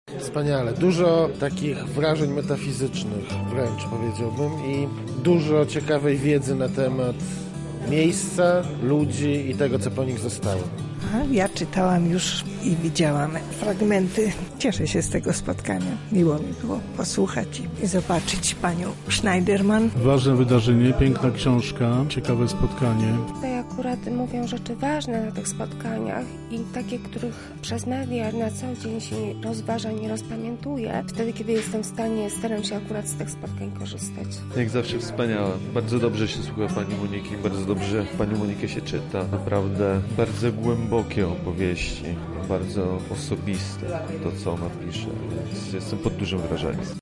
W  tamtym miejscu słychać było szelest kartek, fragmenty prozy, a na kolanach uczestników leżały zielone druki.
– Ten temat jest nam bliski, to czy nam się podobało jest pytaniem retorycznym – komentowali zebrani.